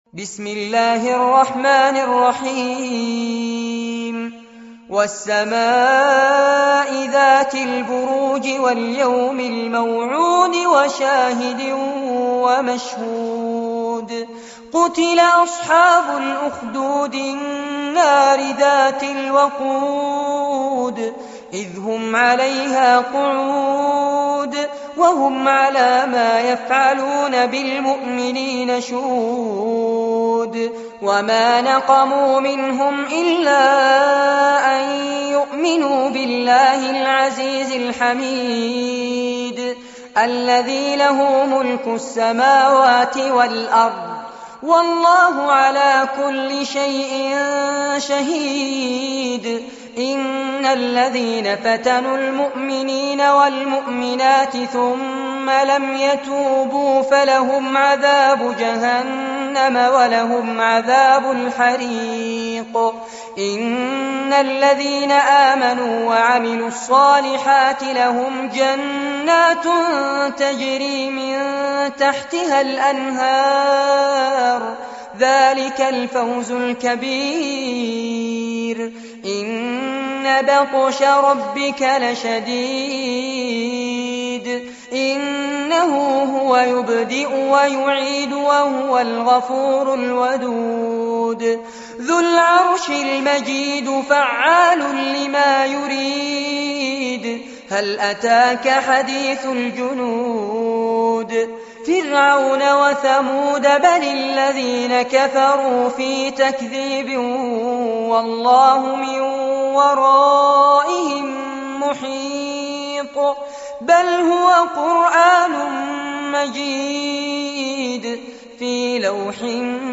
عنوان المادة سورة البروج- المصحف المرتل كاملاً لفضيلة الشيخ فارس عباد جودة عالية